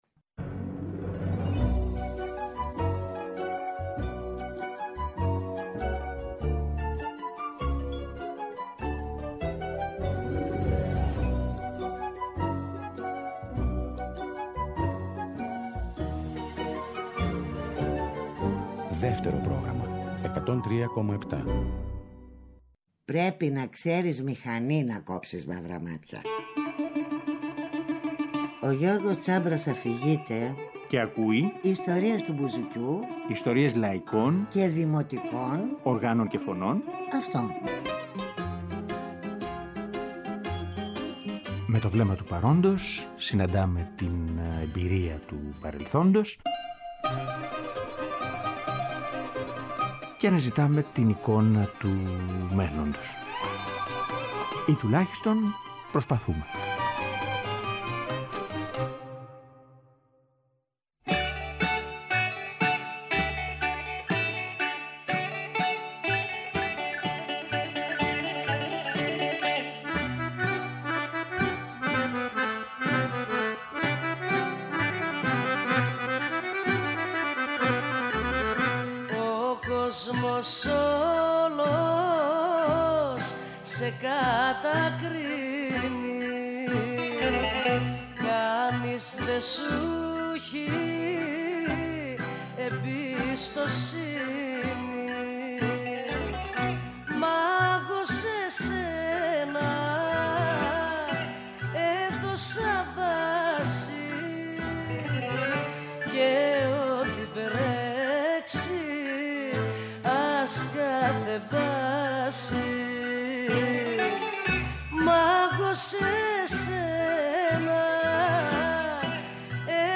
Μικρή επιλογή από εκείνη την μεταβατική εποχή στη σημερινή εκπομπή, με λίγα λόγια που προσπαθούν να περιγράψουν πως το λαϊκό τραγούδι του «τζουκ μποξ» δέχτηκε «δάνεια» από τα χρόνια του γραμμοφώνου.